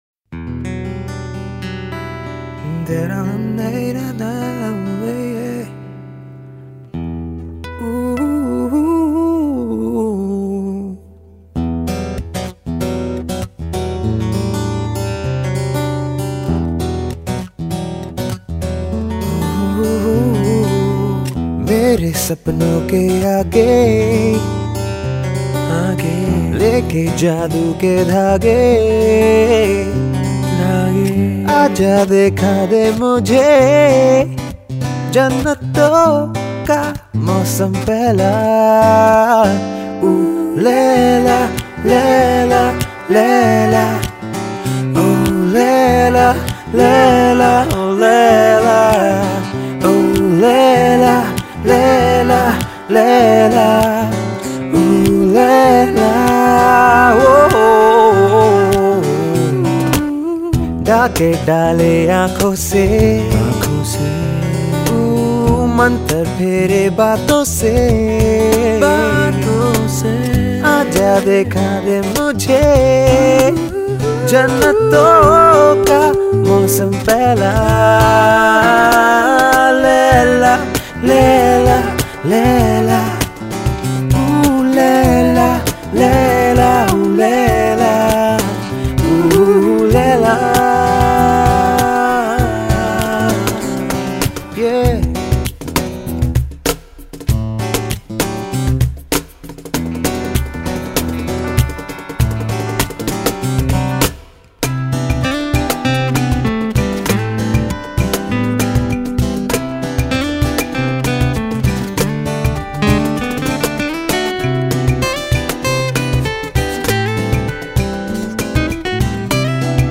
unplugged